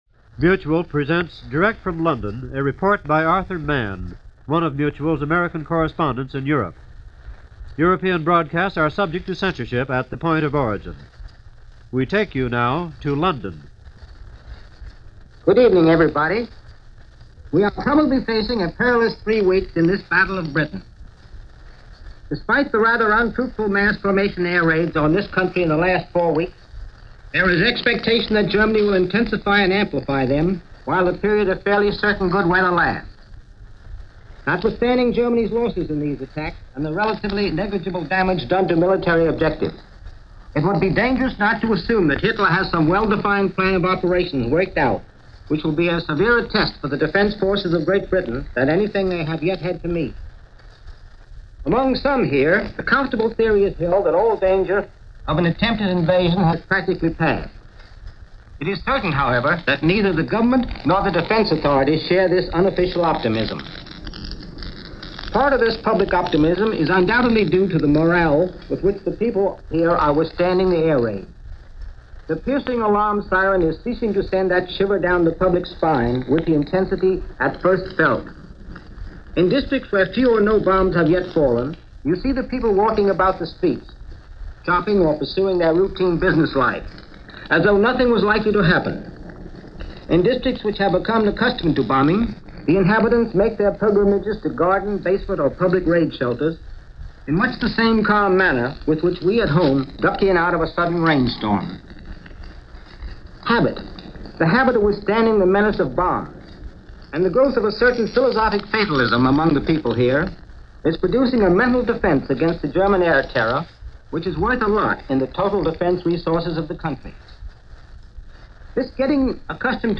Even during preparation for this broadcast, air raid sirens went off, but work continued.